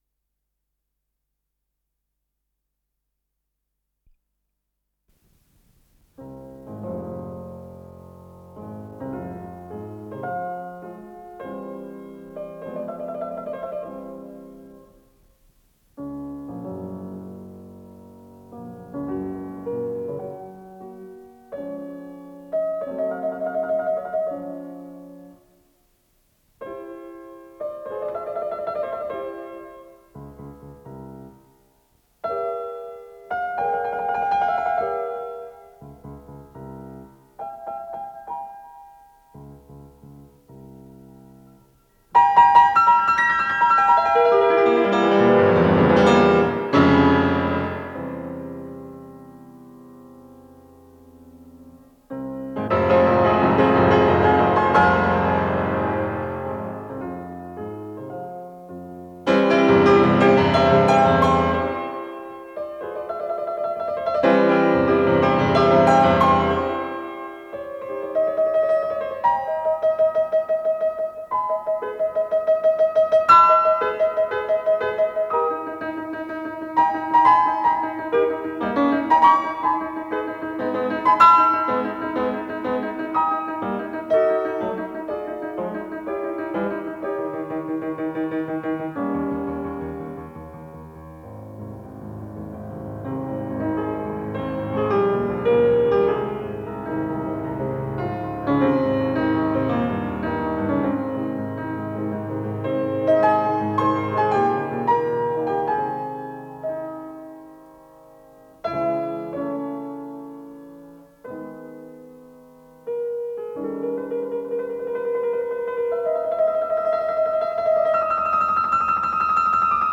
Исполнитель: Вера Горностаева - фортепиано
Для фортепиано
фа минор